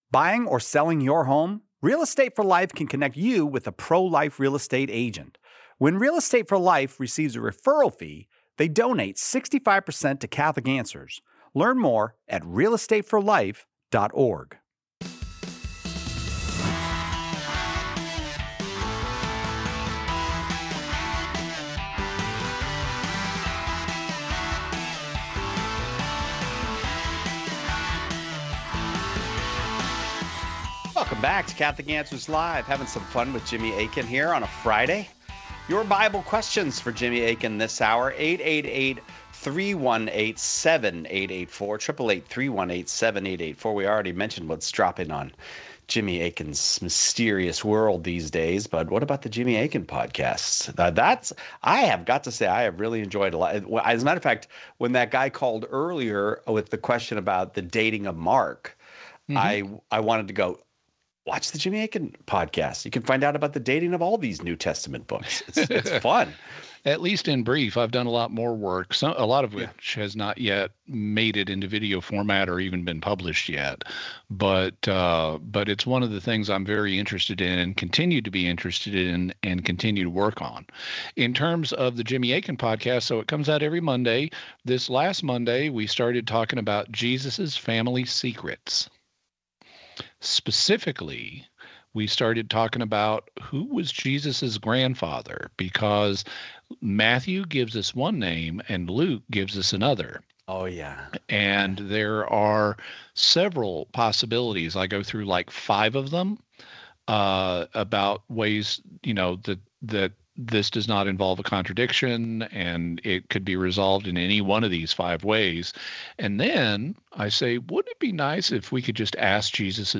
A thoughtful discussion on salvation, Scripture, and Catholic theology.